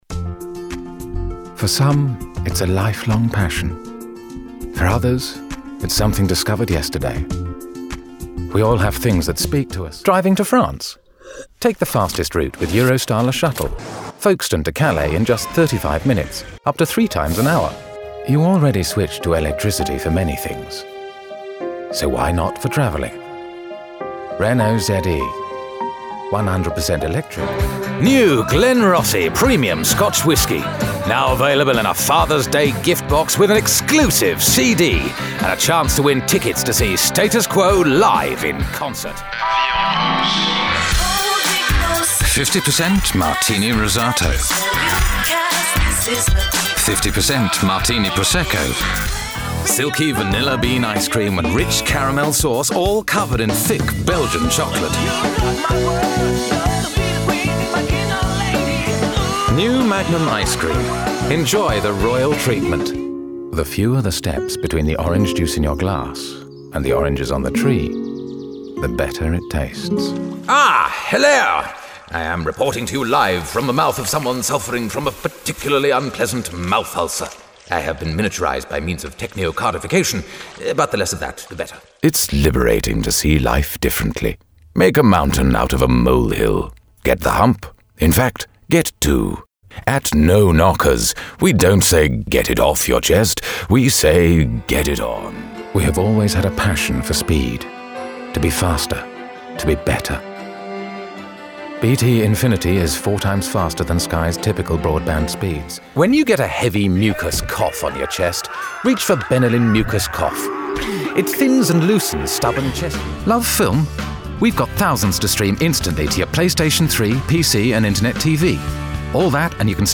Sprechprobe: Werbung (Muttersprache):
Deep, velvety, epic and sophisticated. RP accent & a great understanding of language